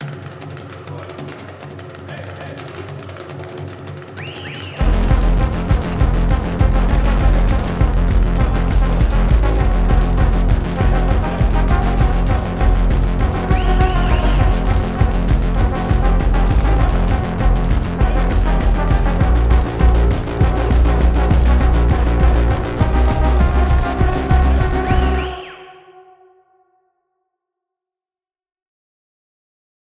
Kategorie: Stückssongs, Sounds und Schauspiel